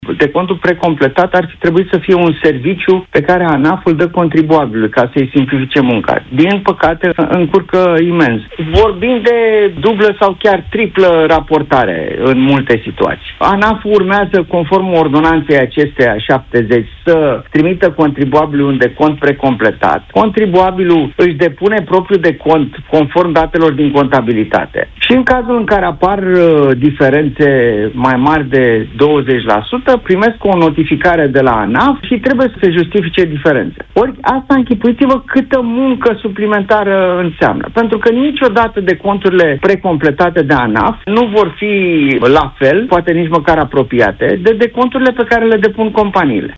Gabriel Biriș, avocat specializat în fiscalitate, a explicat în emisiunea Deșteptarea” de la Europa FM că noile reguli în loc să ușureze activitatea contabililor, mai mult o încurcă, iar măsura nu contribuie la reducerea evaziunii fiscale.